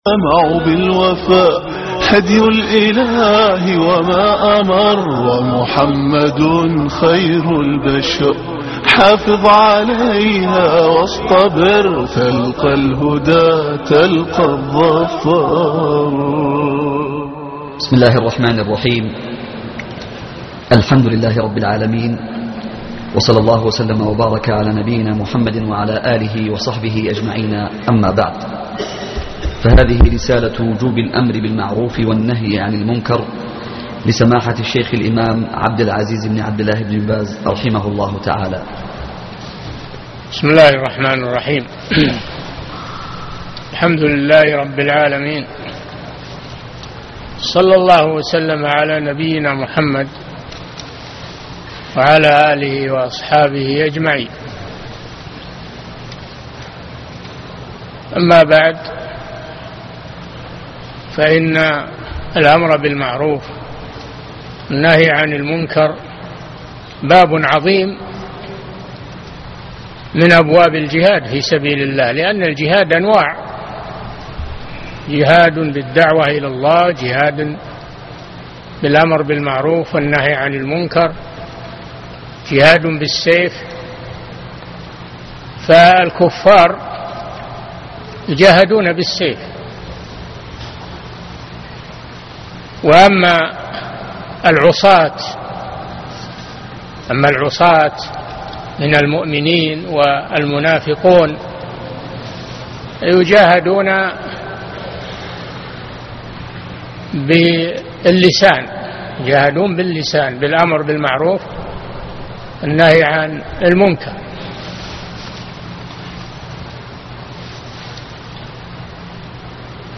تعليق على رسالة وجوب الأمر بالمعروف والنهى عن المنكر -لابن باز( 3/11/2013)محاضرة اليوم - الشيخ صالح بن فوزان الفوازان